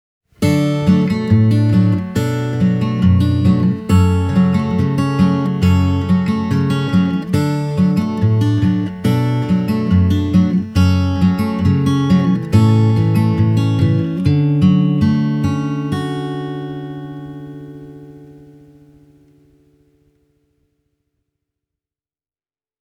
Walden’s CD4040-CERT is a beautiful-looking and -sounding guitar – and it’s also quite loud. The bottom end is fat and muscular, without ever mushing out, while the midrange is vocal and clear.
Walden CD4040-CERT – fingerstyle